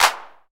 clap.ogg